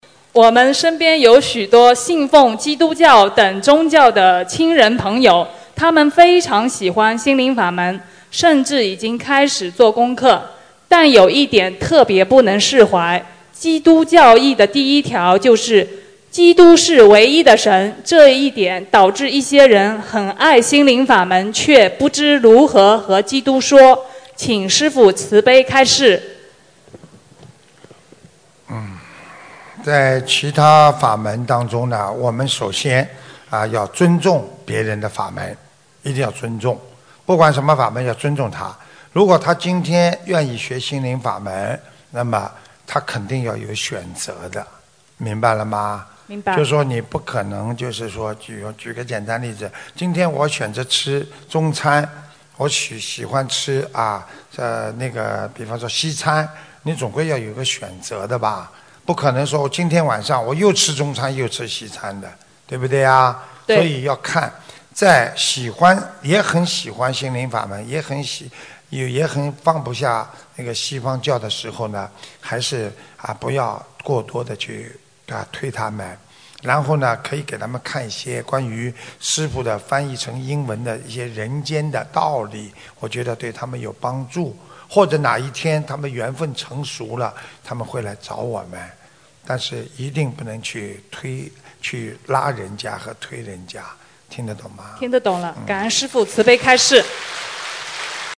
如何帮助信奉其他宗教但又想修心靈fǎ門的人┃弟子提问 师父回答 - 2017 - 心如菩提 - Powered by Discuz!